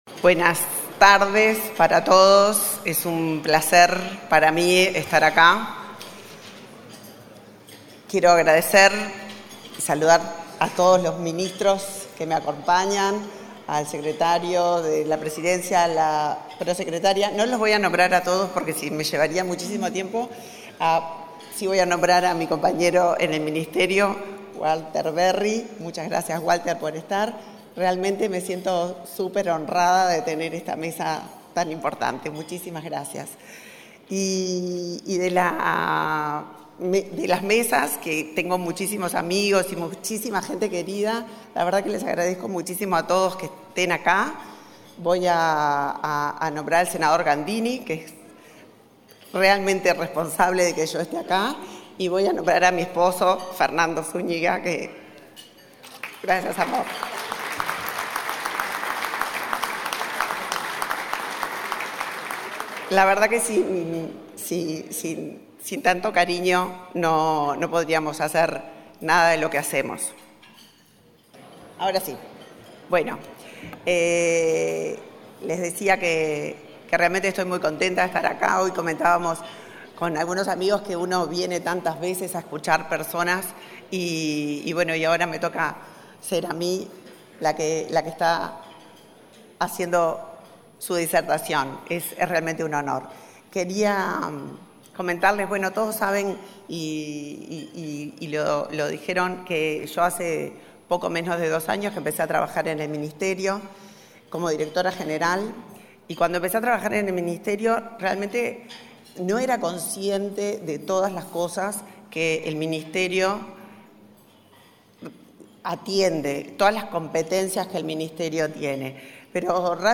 Disertación de la ministra del MIEM, Elisa Facio, en ADM
La titular del Ministerio de Industria, Energía y Minería (MIEM), Elisa Facio, disertó, este 25 de setiembre, en un almuerzo de trabajo de la